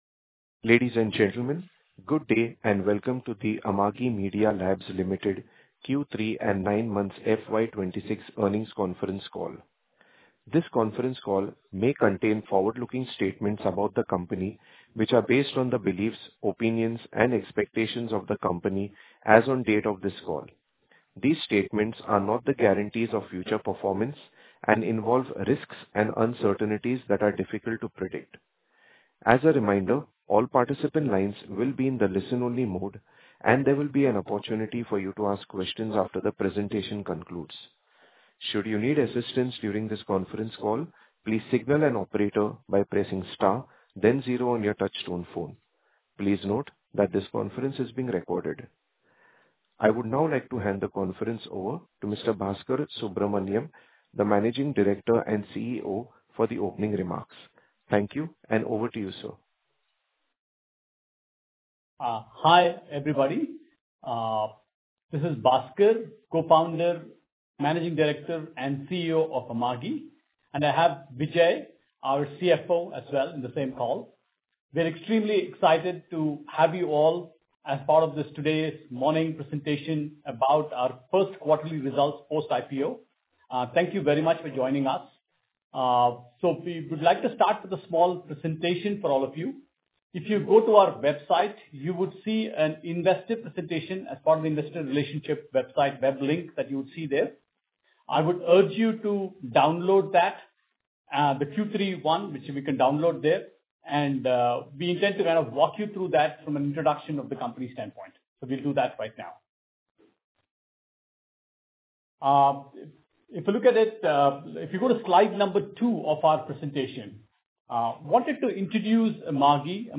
Earnings Call Audio Recording.mp3